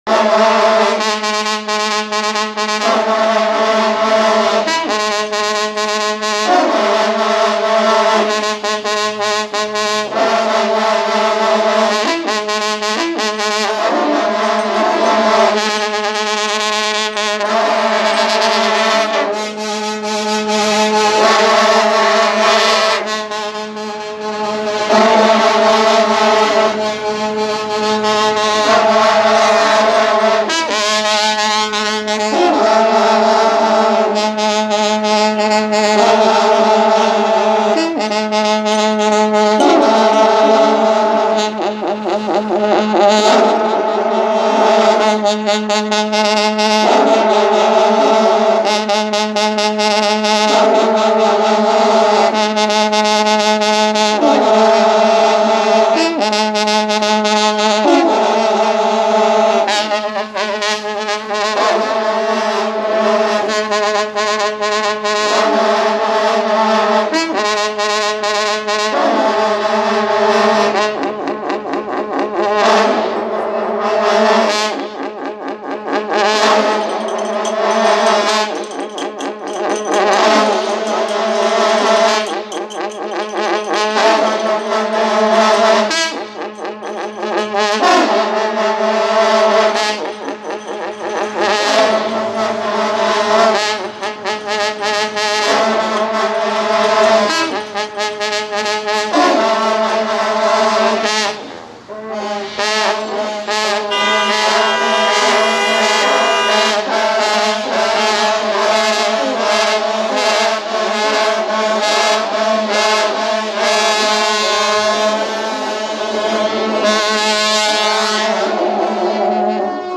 Звучание традиционных инструментов в музыкальных композициях